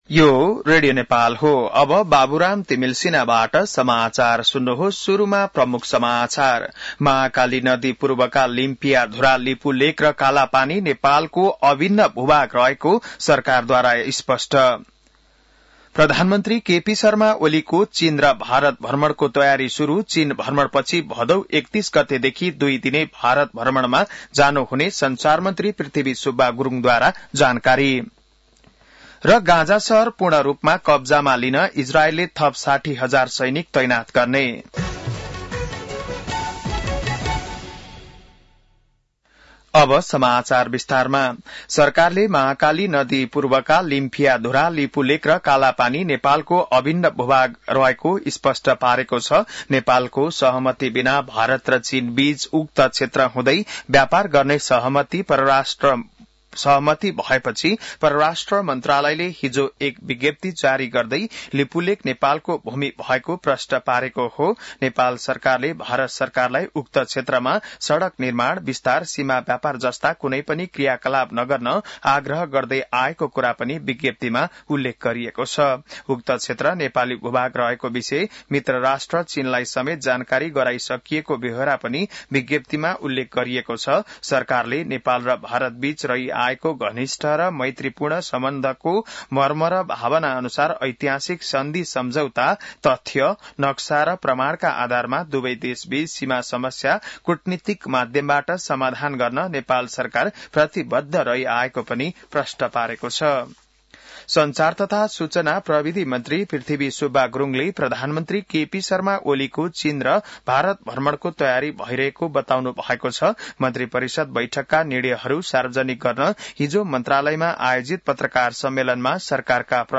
बिहान ९ बजेको नेपाली समाचार : ५ भदौ , २०८२